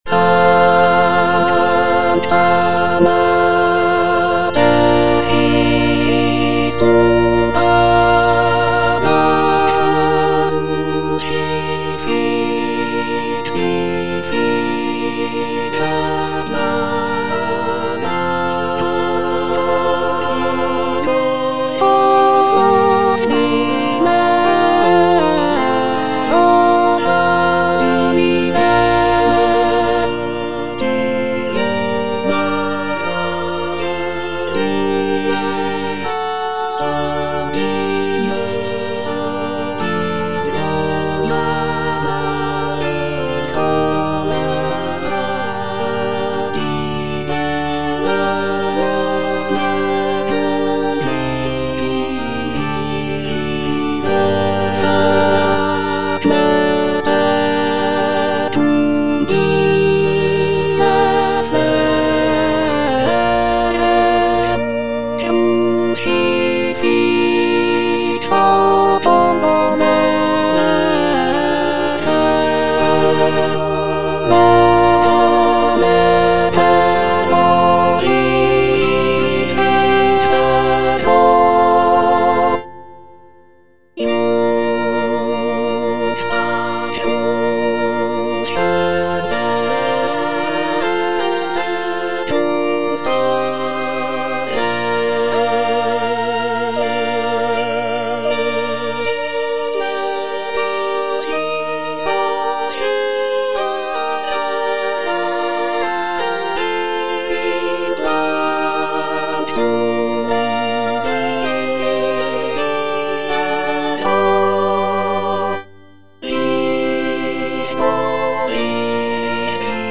G.P. DA PALESTRINA - STABAT MATER Sancta Mater, istud agas (alto II) (Emphasised voice and other voices) Ads stop: auto-stop
sacred choral work
Composed in the late 16th century, Palestrina's setting of the Stabat Mater is known for its emotional depth, intricate polyphonic textures, and expressive harmonies.